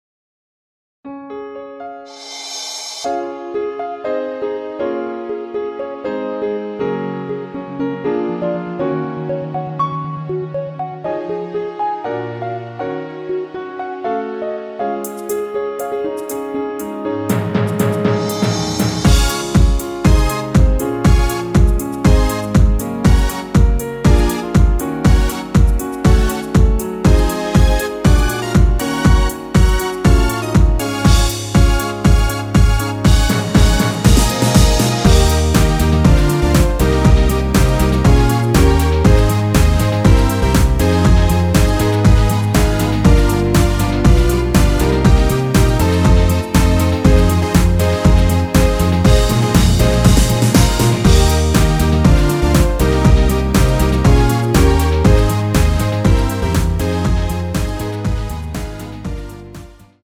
원키에서(+2)올린 MR입니다.
전주 없이 시작 하는곡이라 전주 1마디 만들어 놓았습니다.(미리듣기 참조)
Db
앞부분30초, 뒷부분30초씩 편집해서 올려 드리고 있습니다.
중간에 음이 끈어지고 다시 나오는 이유는